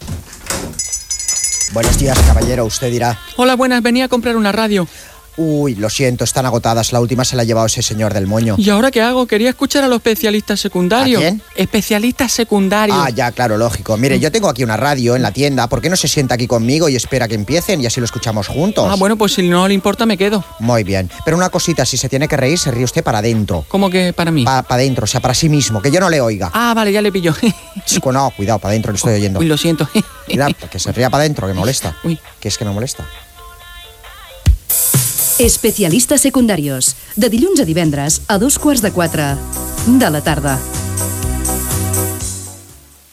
Promoció del programa
FM